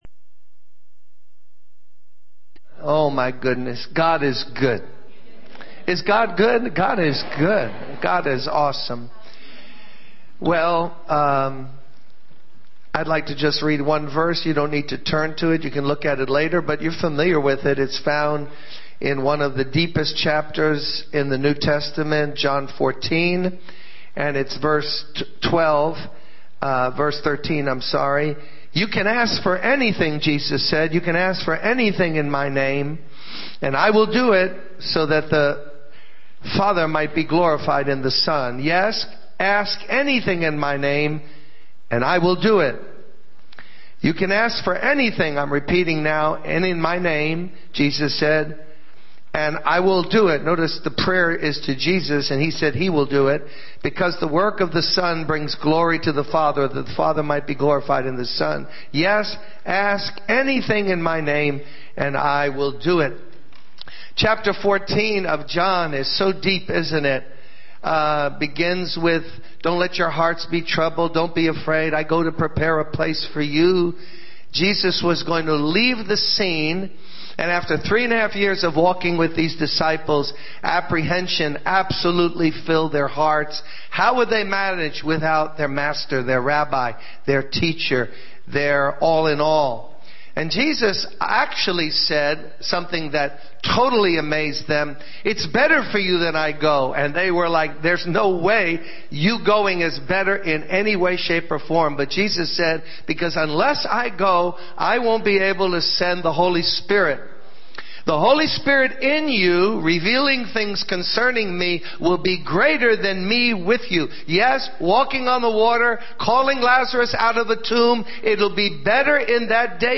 This sermon emphasizes the importance of recognizing that everything is for God's glory and everything comes from God. It delves into the need for complete reliance on God for transformation and provision, highlighting the power of prayer in Jesus' name and the significance of humbly acknowledging our dependence on God for all aspects of life.